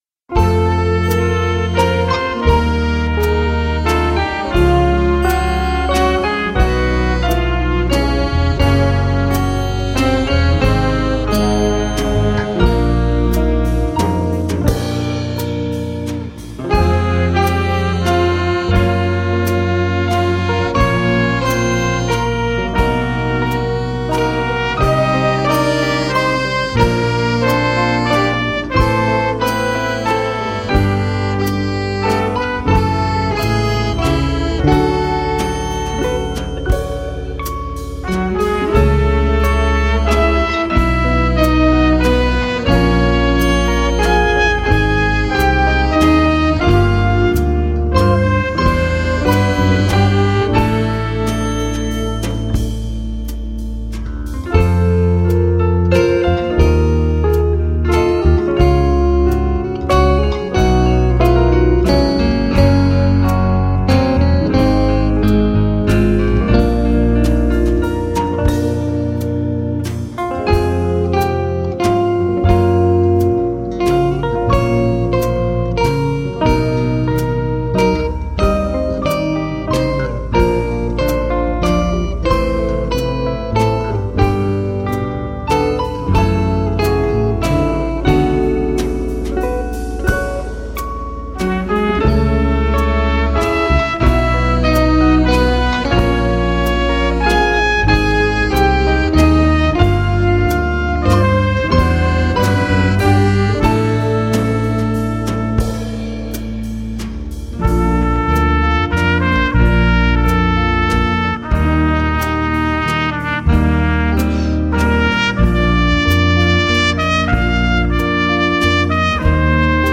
Style funk, fusion